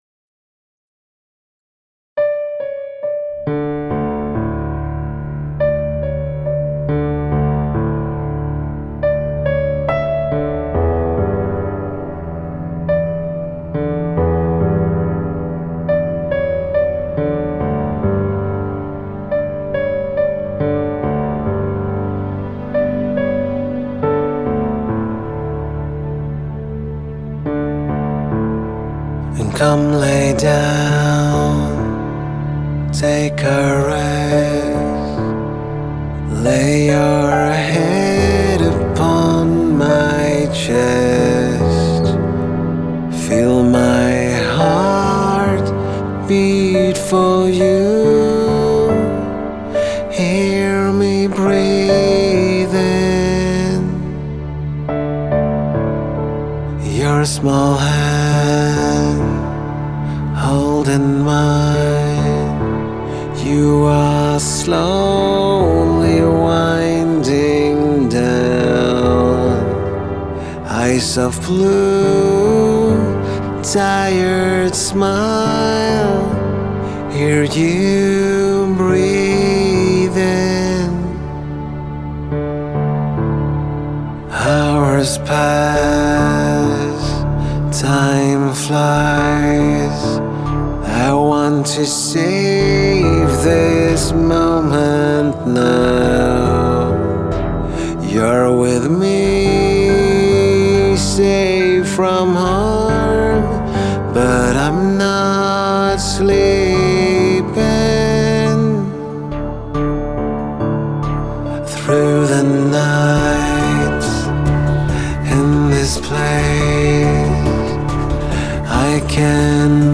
Vacker och stämningsfull visa.
Jättefin låt med stämningsfullt arr. Framför allt gillar jag den nakna sångstämman.
breathing4.mp3